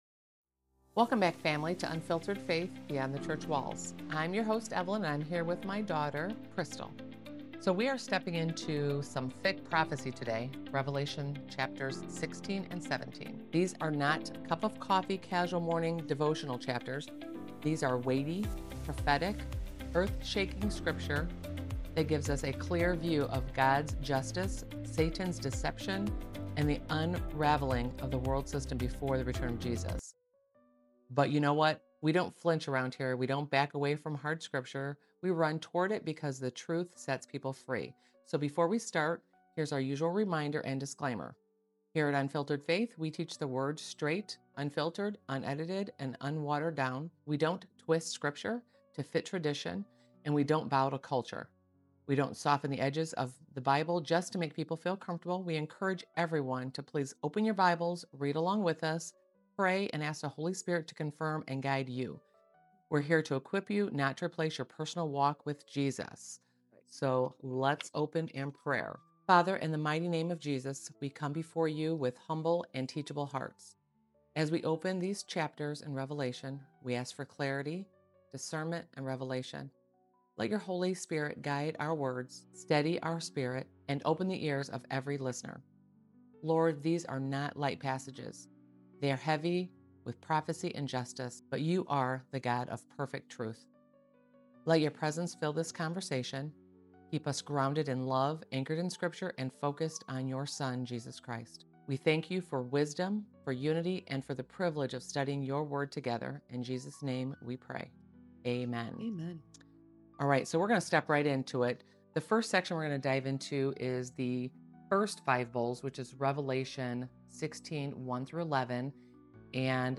Revelation 16–17 comes alive in this deep, verse-by-verse teaching!